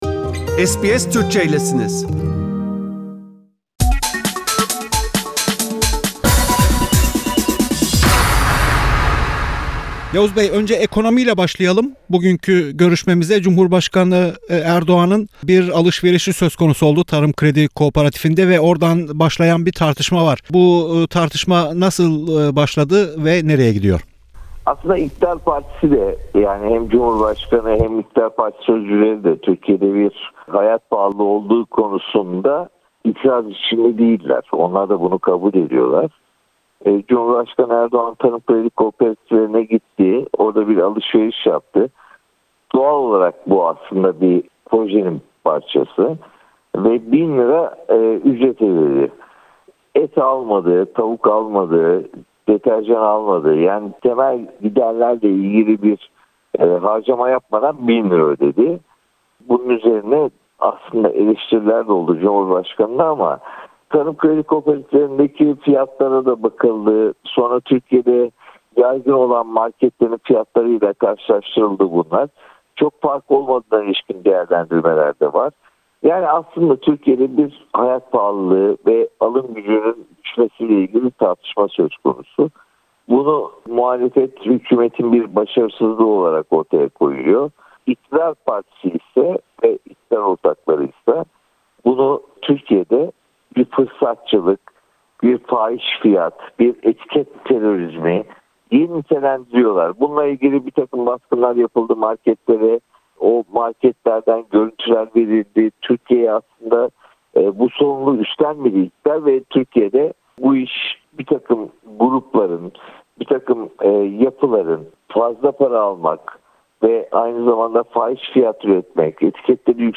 Gazeteci Yavuz Oğhan ile Türkiye gündemini değerlendirdik.